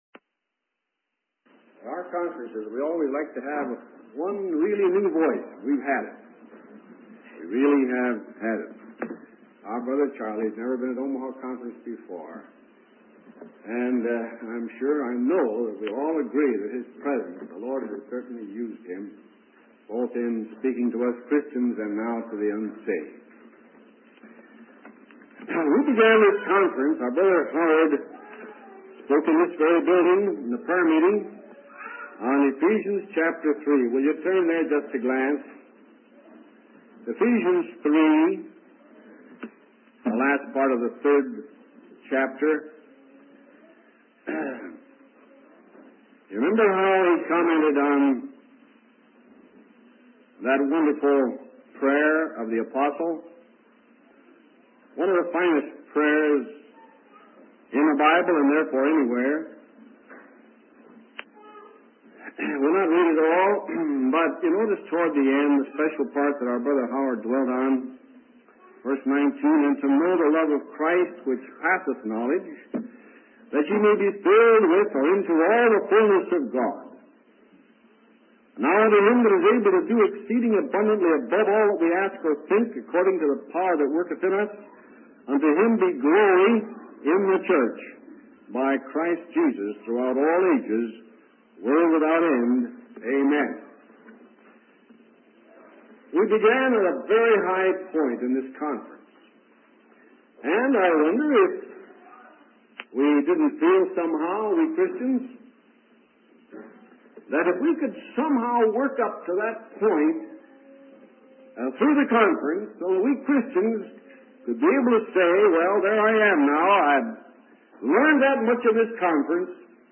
In this sermon, the preacher emphasizes the state of humanity as described in the Bible. He highlights seven aspects of our condition: being dead in trespasses and sins, walking according to the ways of the world, influenced by the power of the air, living in disobedience, following the desires of the flesh and mind, being children of wrath, and being like others in our fallen state.